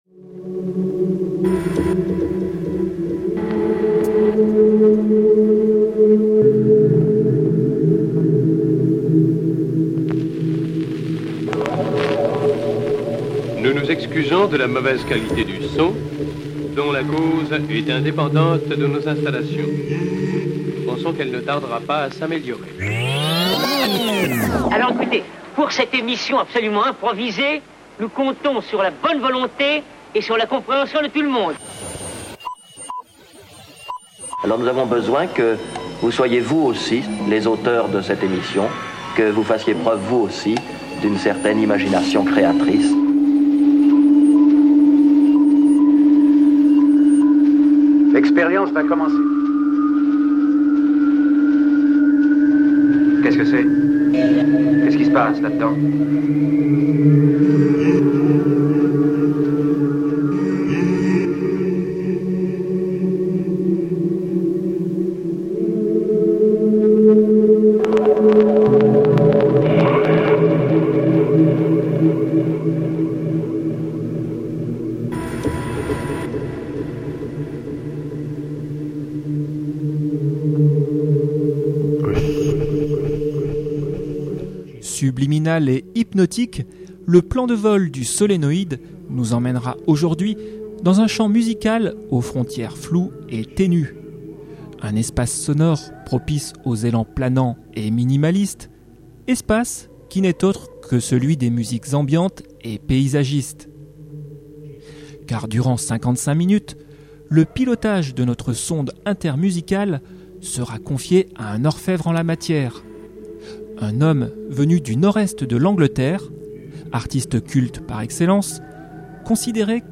folk aérienne
krautrock